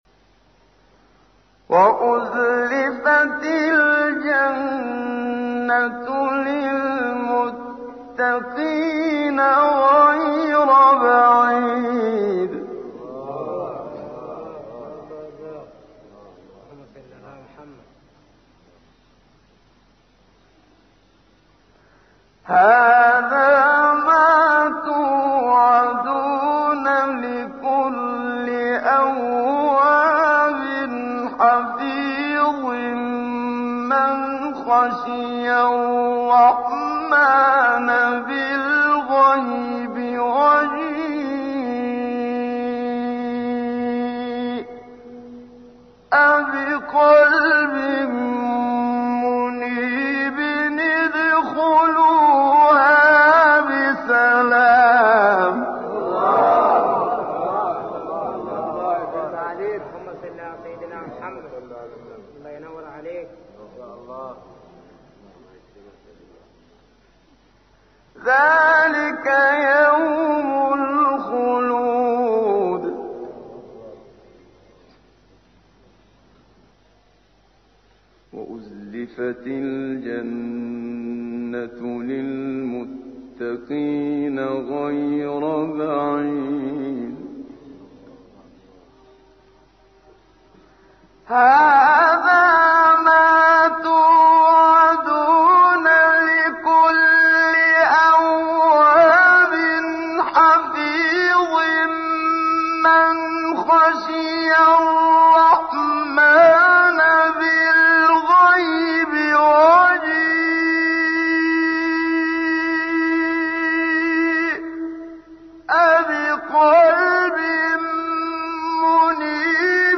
گروه شبکه اجتماعی: فرازهایی از تلاوت قاریان بنام کشور مصر ار جمله شیخ رفعت، احمد صالح، عبدالفتاح شعشاعی را می‌شنوید.